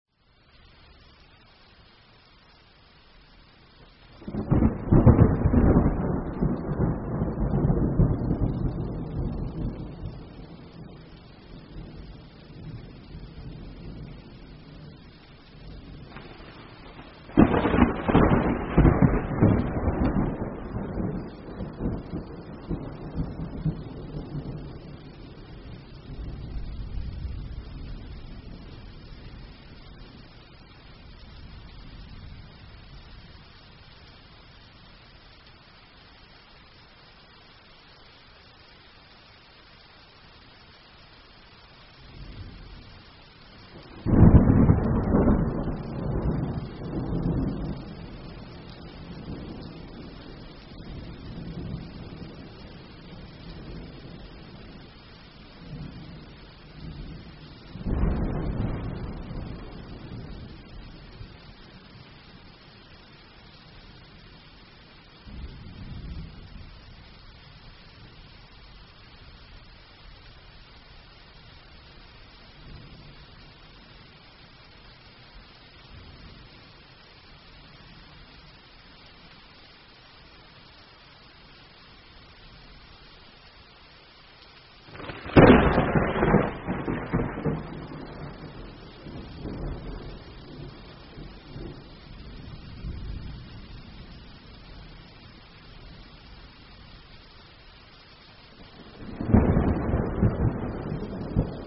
Звуки грозы, грома
Звук грози вдали, що віддаляється